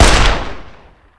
wpn_fire_357revolver.wav